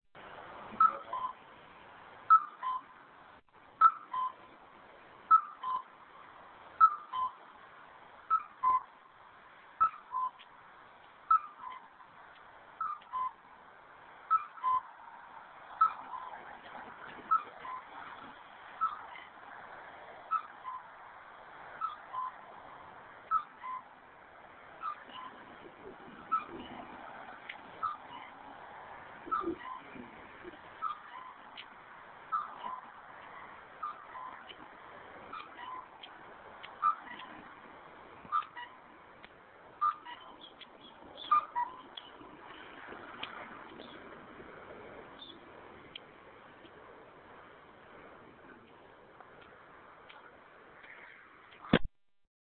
Intersection
Crosswalk signals for the blind.